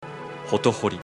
ho-to-ho-ri' as short, concise syllables.
hotohori_pronunc.mp3